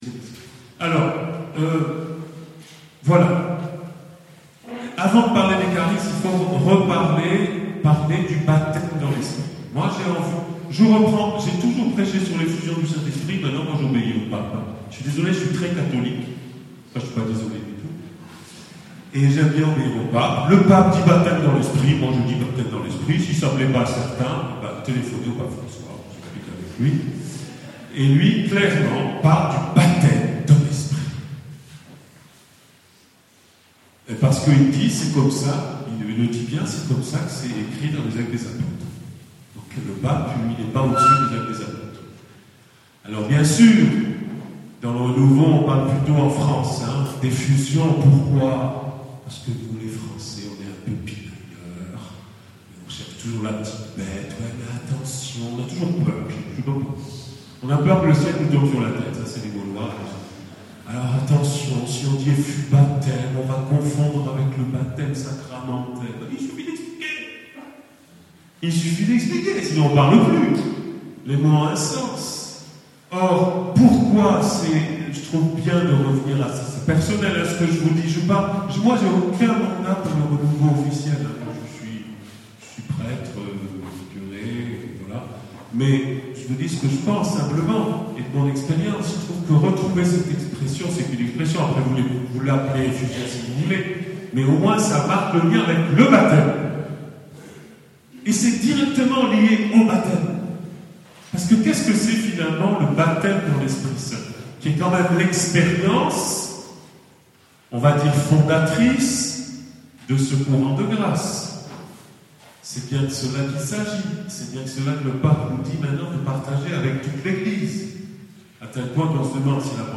Enseignement
En raison de mauvaises conditions d'enregistrement, la qualité du son est légèrement altérée.
Format :MP3 64Kbps Mono